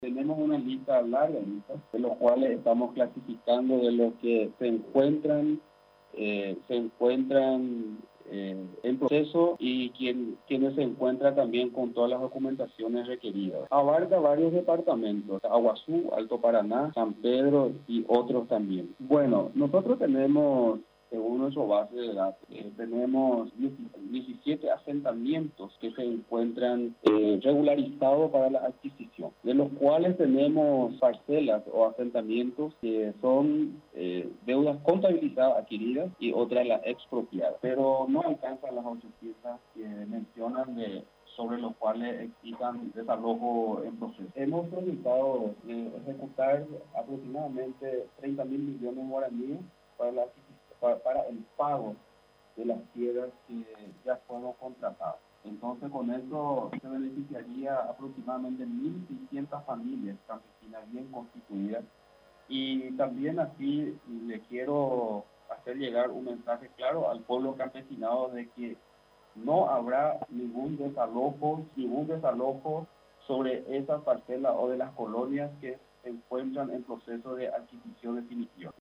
El presidente del Instituto de Desarrollo Rural y de la Tierra (INDERT), Mario Vega, aseguró este lunes, a través de una rueda de prensa, que no habrá desalojos en las colonias que se encuentran en proceso de adquisición definitiva.